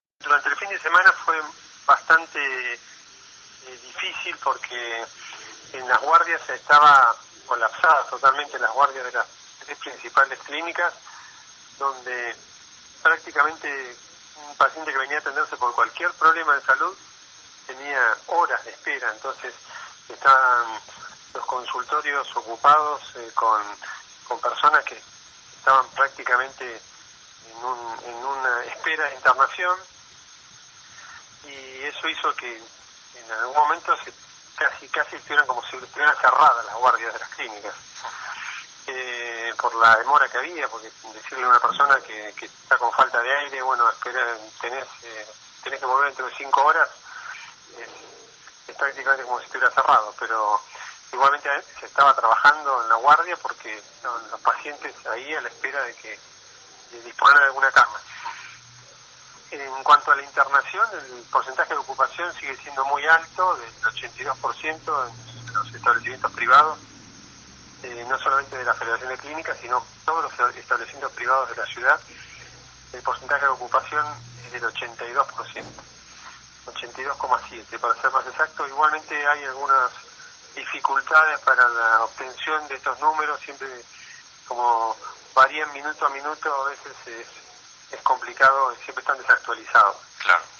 decía esto a los medios radiales, entre ellos 0223radio: